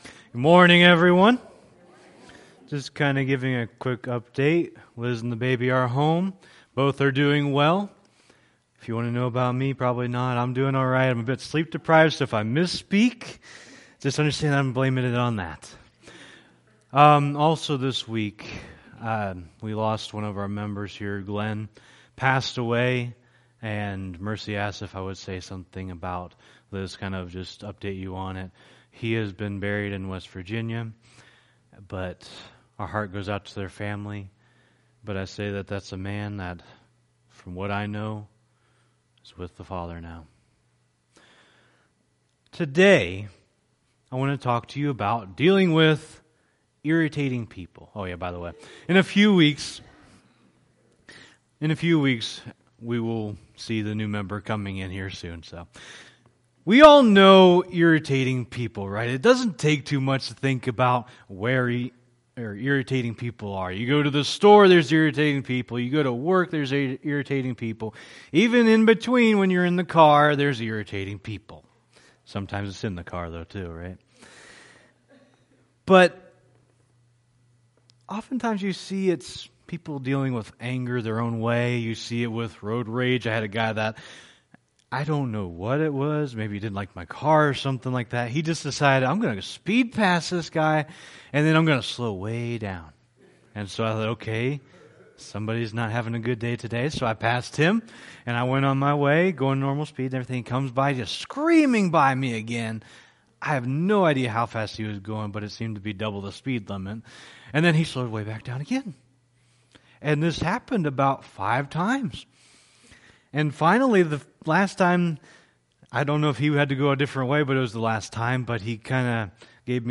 Series: Sermon on the Mount Tagged with anger